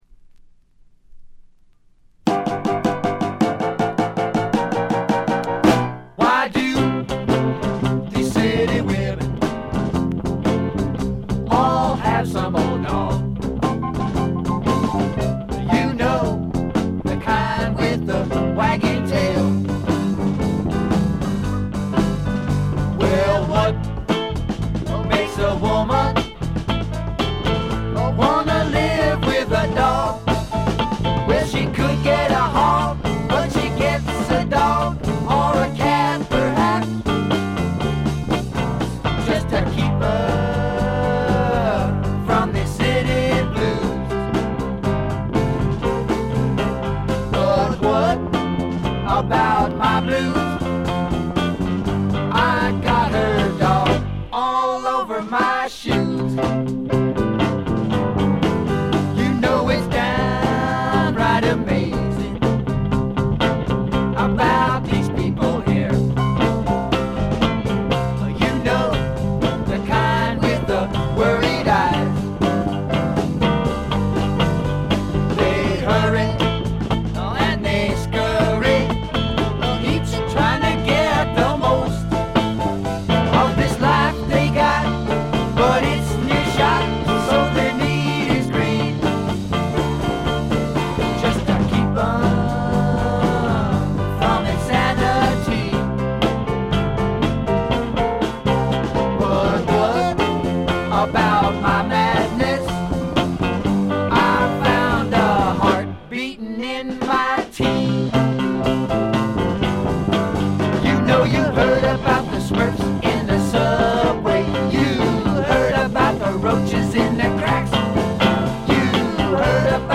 軽微なバックグラウンドノイズ。散発的なプツ音が少し。
試聴曲は現品からの取り込み音源です。